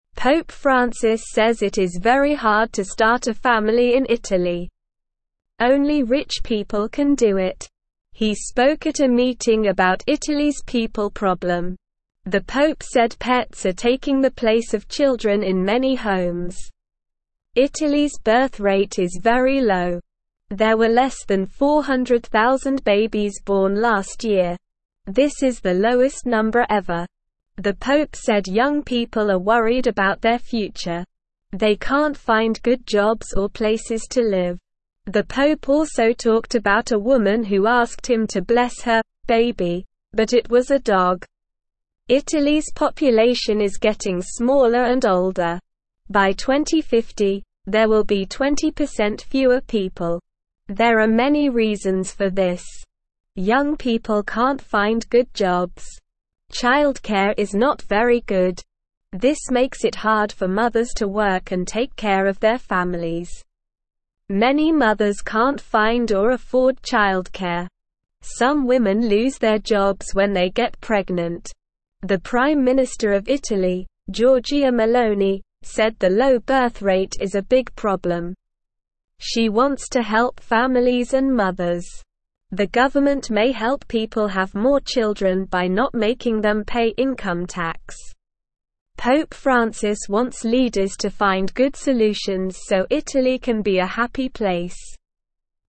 Slow
English-Newsroom-Beginner-SLOW-Reading-Pope-Talks-About-Italys-Baby-Problem.mp3